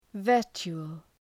Προφορά
{‘vɜ:rtʃu:əl}